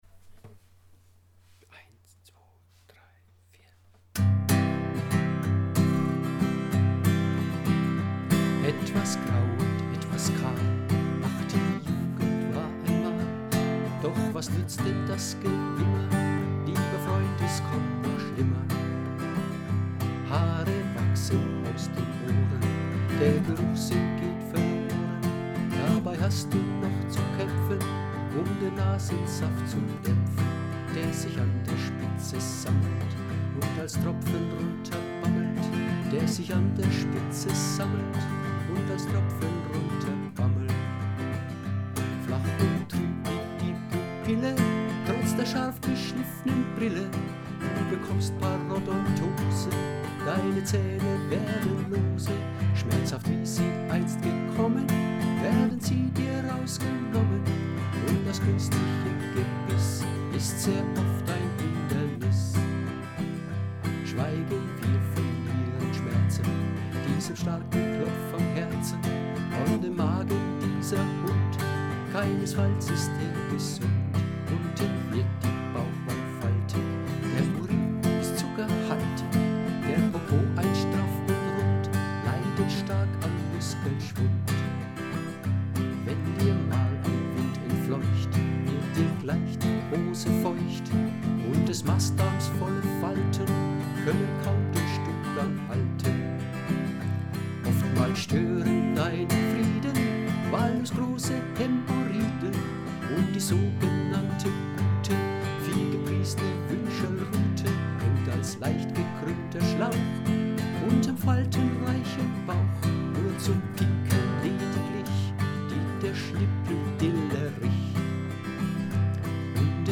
Als "untypischer" Alleinunterhalter komme ich nur mit meiner Gitarre und meinem Gesang zu Ihnen und spiele einen breitgefächerten Querschnitt von Rock- und Pop-Klassikern der 50er bis 90er-Jahre.
Geburtstagslied-Playback-mit-Gesang_0146.MP3